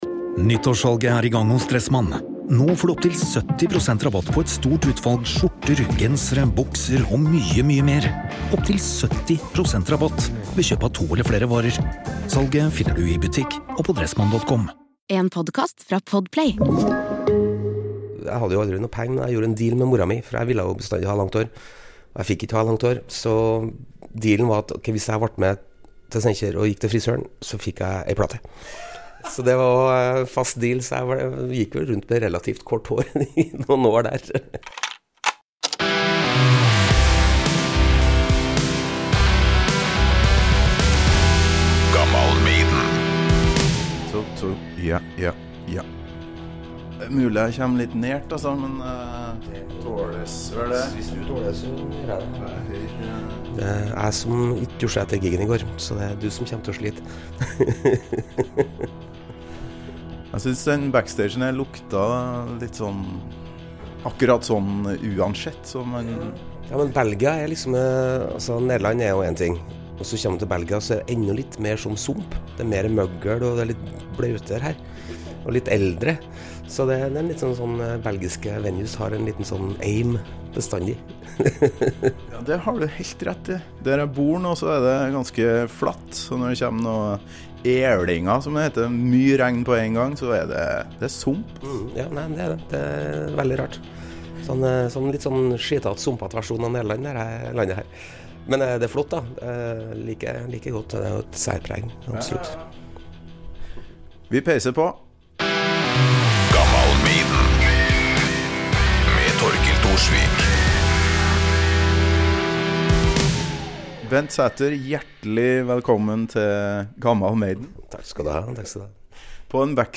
Beschreibung vor 7 Monaten Bli med backstage før Motorpsycho-konsert i Leuven, Belgia for en goood, oppklarende og nerdete prat med bassist, vokalist og låtskriver Bent Sæther.
Tenker det oppsummerer 1/10 av denne artige, frie episoden fra en stinkende, belgisk backstage.